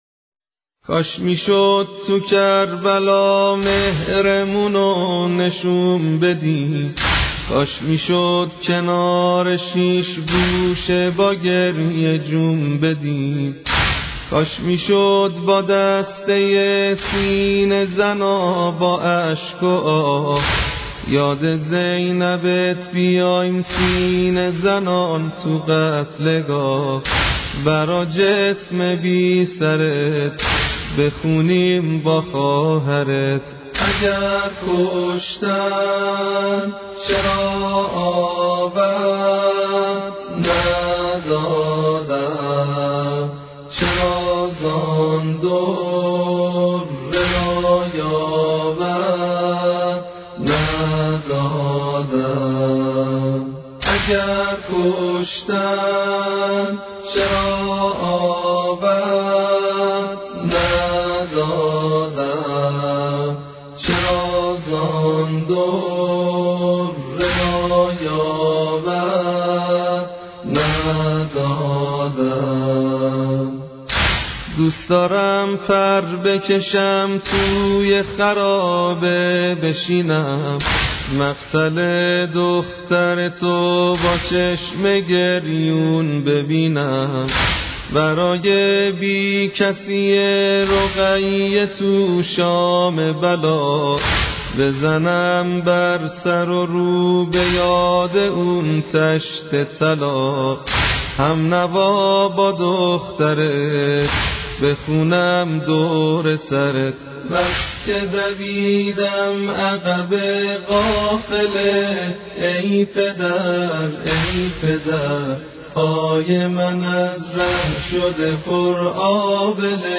مداحی
مرثیه